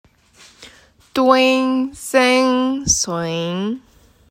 最近看 电视剧学到最逗的发音